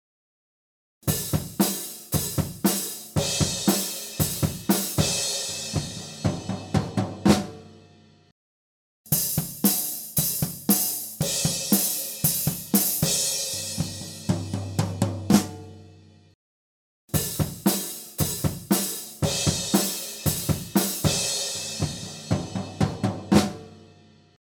Διερεύνηση στερεοφωνικών τεχνικών ηχογράφησης και μίξης κρουστού οργάνου (drums) μέσω διαφορετικών στερεοφωνικών τεχνικών μικροφώνων.